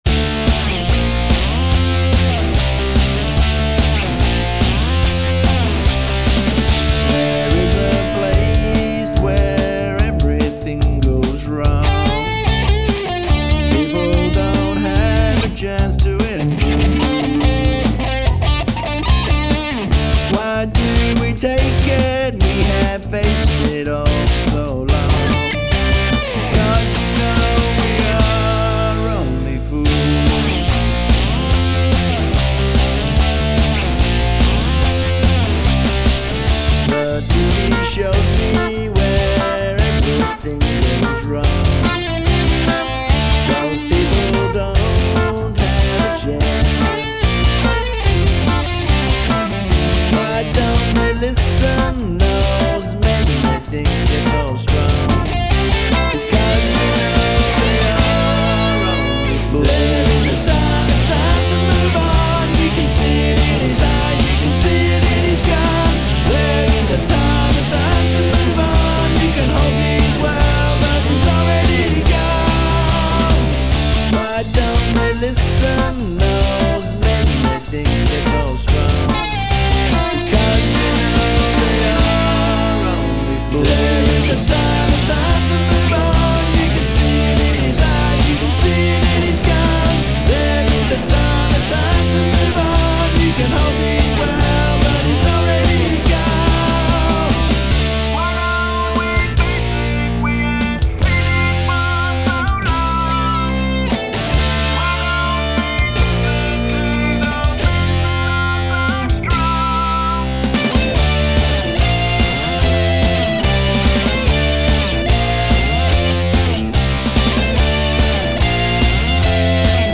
Recorded in 2007 is my first protest song.